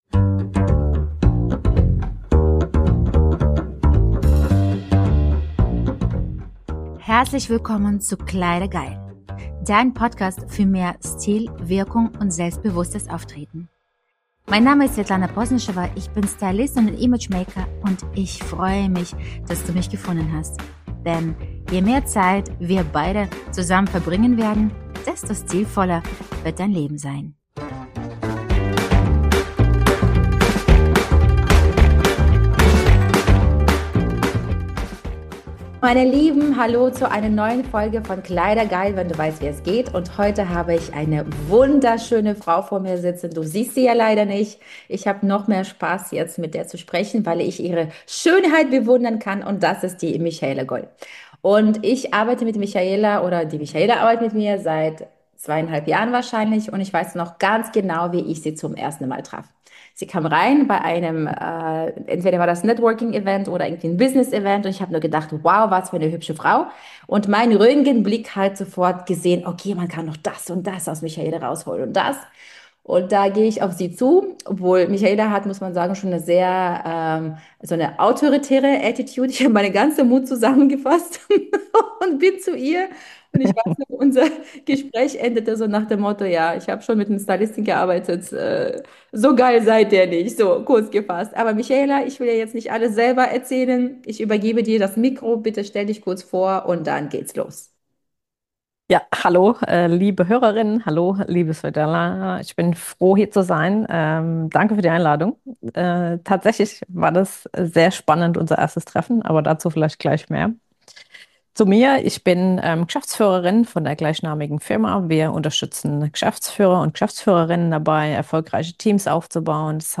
Interview ~ ”Kleider geil!” Wenn du weißt, wie es geht Podcast